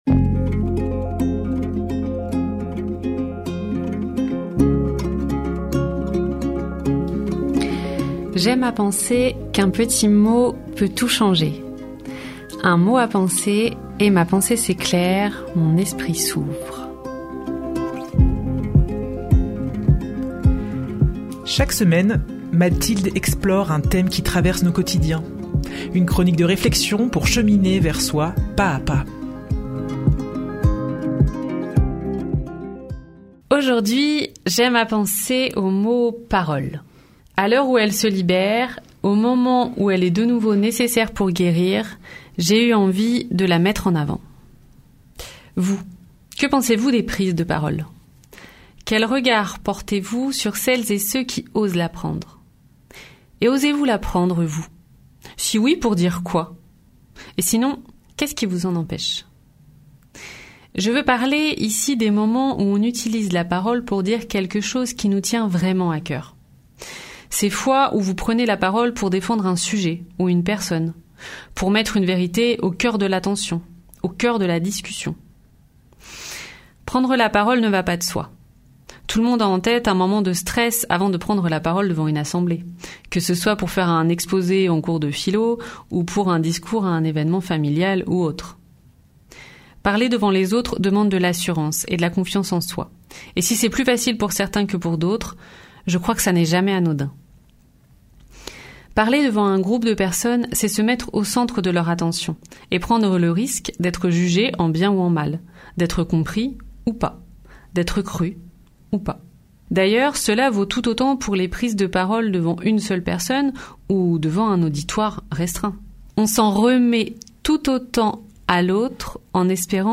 Une nouvelle chronique de réflexion pour cheminer vers soi pas-à-pas.